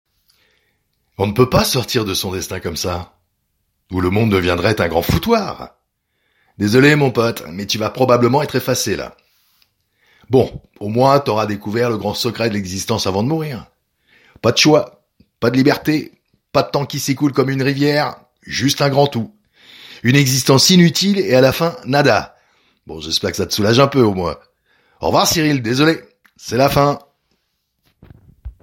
Voix off
Baryton